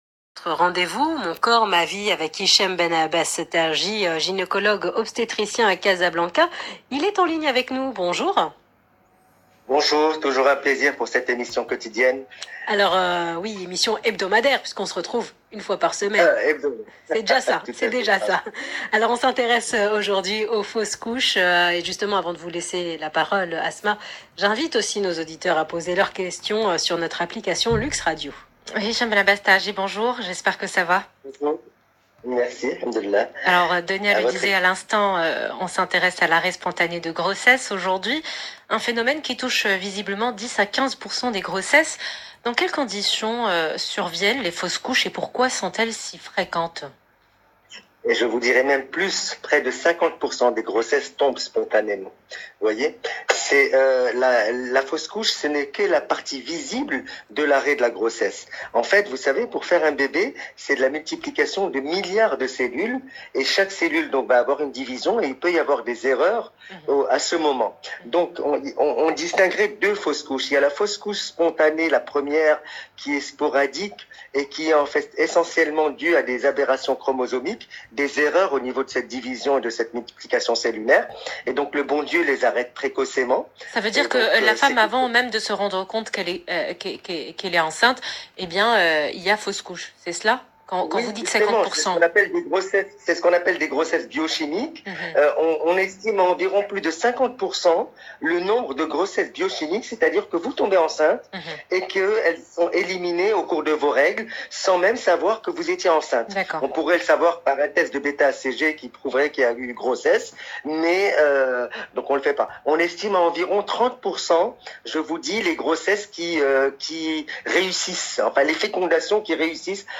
Essai de réponse au cours de cette interview dans l’Heure essentielle sur LUXE RADIO